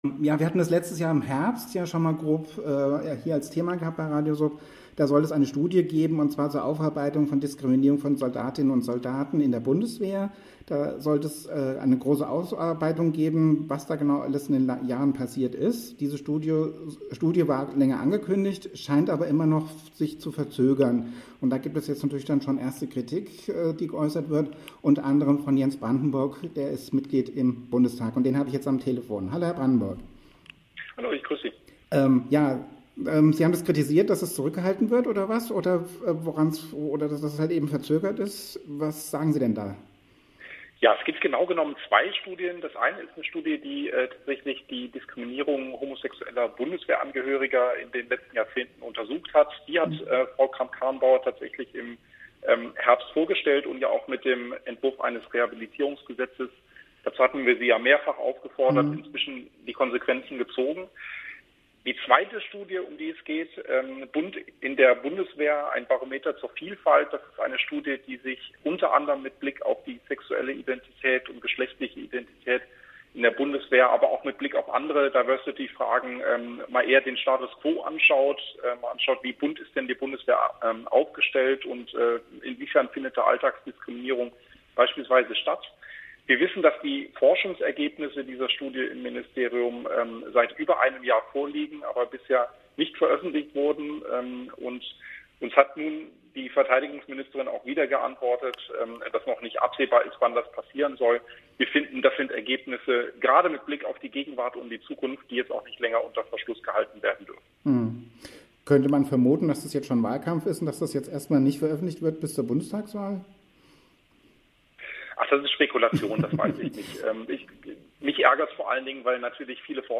spricht darüber mit Jens Brandenburg,
FDP-Bundestagsabgeordneter.